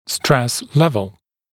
[stres ‘levl][стрэс ‘лэвл]уровень нагрузки, уровень стресса